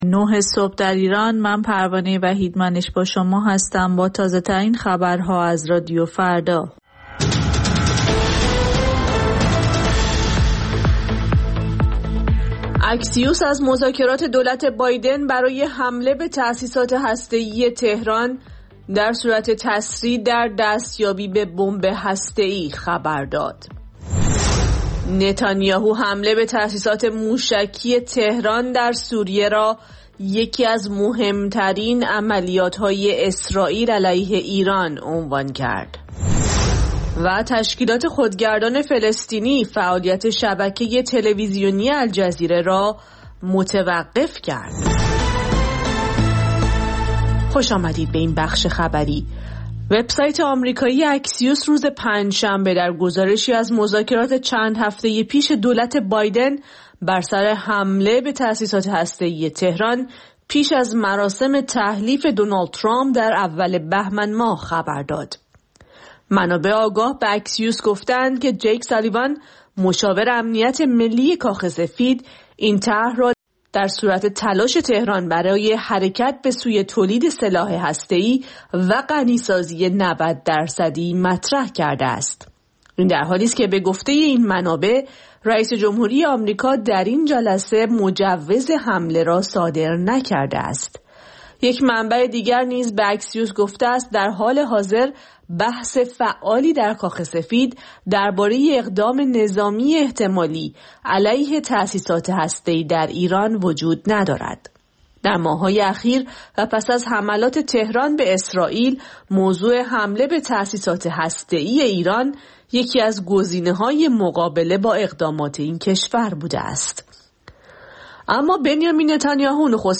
سرخط خبرها ۹:۰۰